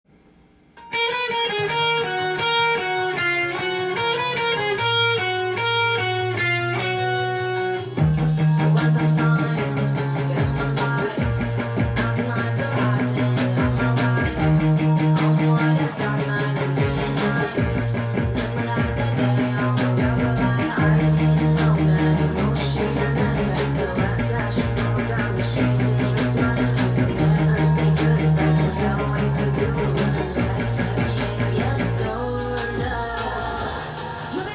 音源に合わせて譜面通り弾いてみました
チューニングは半音下げにすると音源に合わせてtab譜
歌が始まればあとはパワーコードのみです。
弾くと低音が効いたカッコいいギターパートを演奏できます。